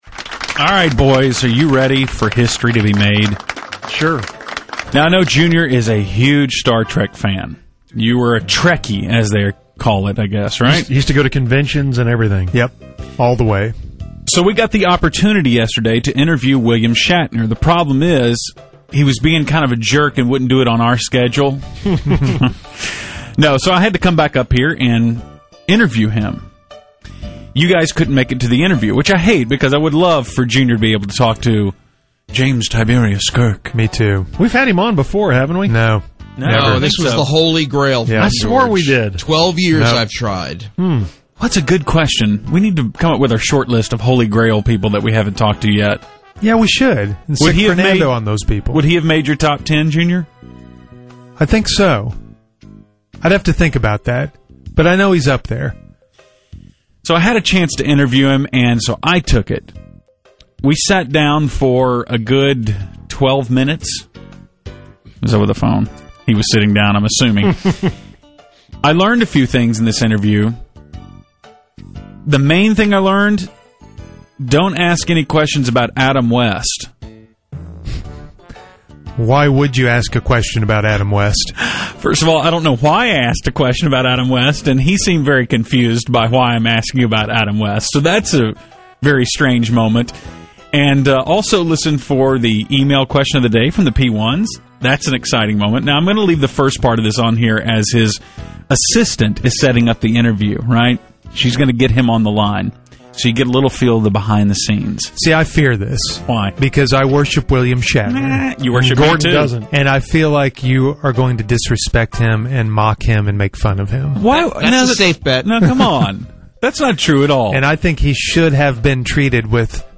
William Shatner Interview